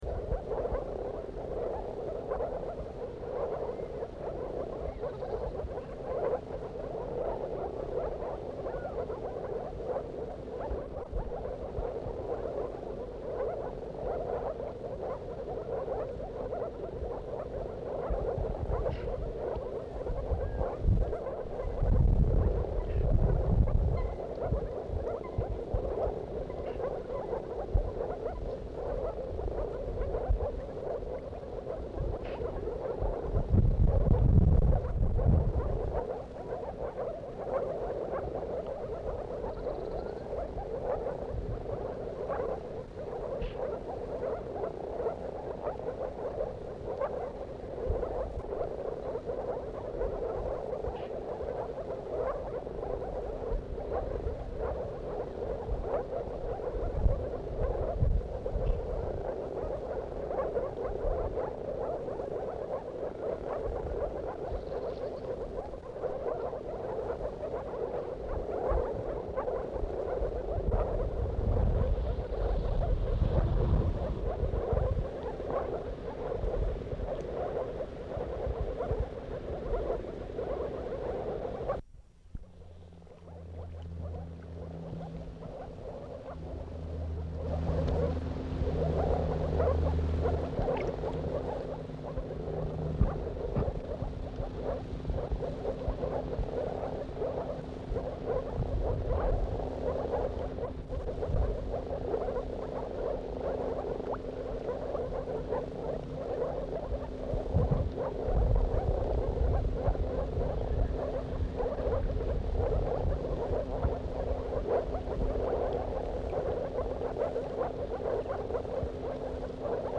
Audiodateien, nicht aus dem Schutzgebiet
Moorfrosch Paarung UB
moorfrösche paarung.mp3